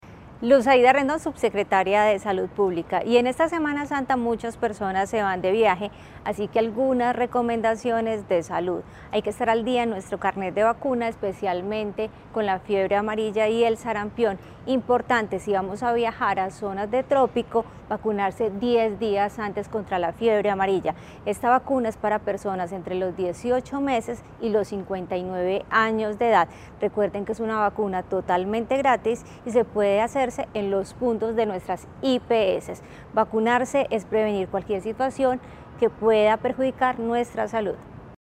Palabras de subsecretaria de Salud Pública, Luz Aida Rendón
Palabras-de-subsecretaria-de-Salud-Publica-Luz-Aida-Rendon.mp3